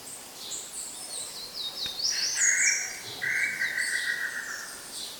INHAMBU-CHINTÃ
Crypturellus tataupa (Temminck, 1815)
Nome em Inglês: Tataupa Tinamou
Local: RPPN Santuário Rã-bugio - Guaramirim SC